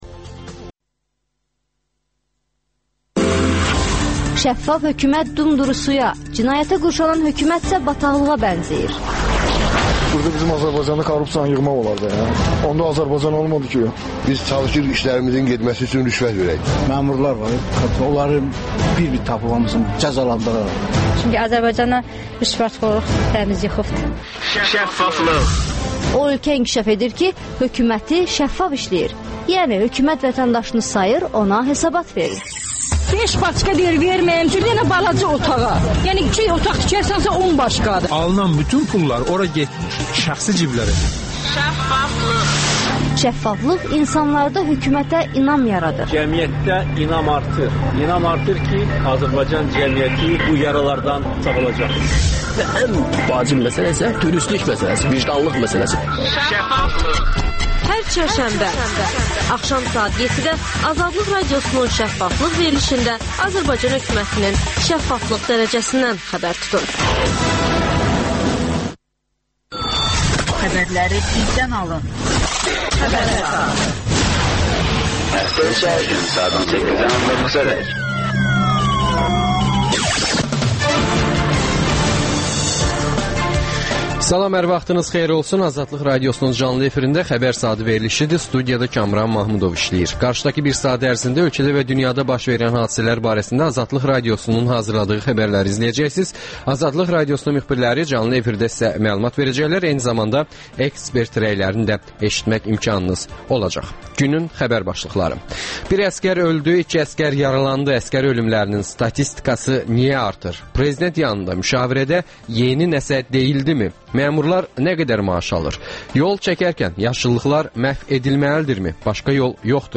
Ölkədə və dünyada baş verən bu və digər olaylar barədə AzadlıqRadiosunun müxbirləri canlı efirdə məlumat verirlər.